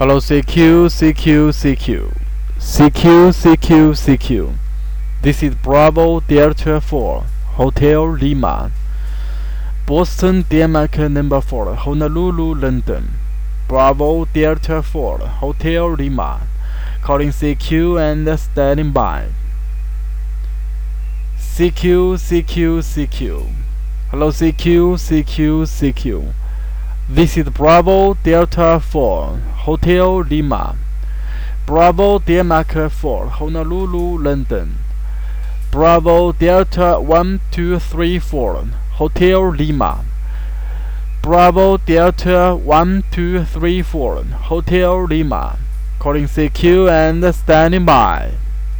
SSB CQ.wav